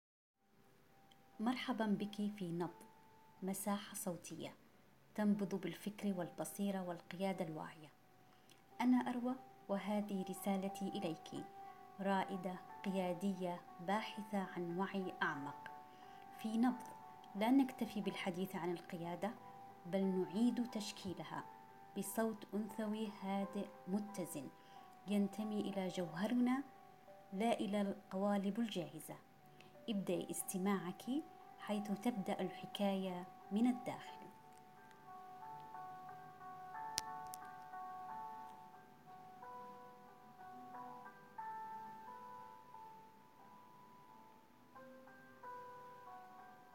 أصوات نسائية تحلل، تُلهم، وتفكك التعقيد.